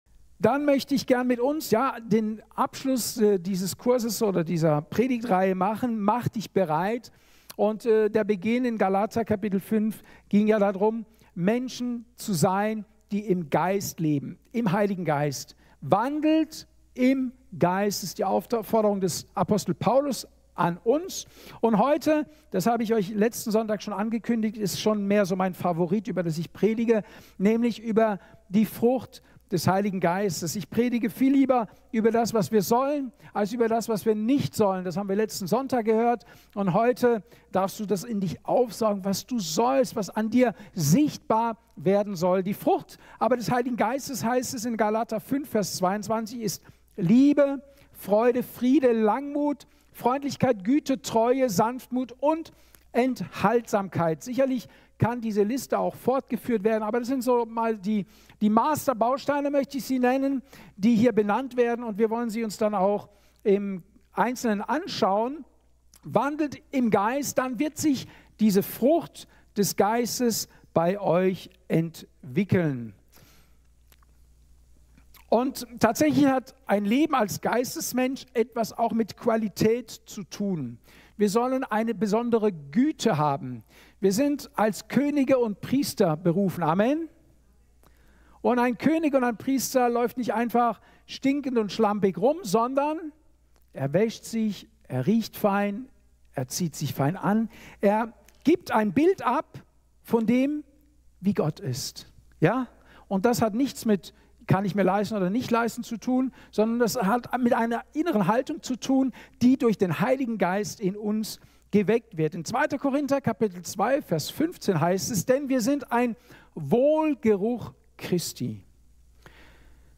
Mach dich bereit - Teil 4/4 Datum: 08.12.2024 Ort: Gospelhouse Kehl